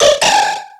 Cri de Manzaï dans Pokémon X et Y.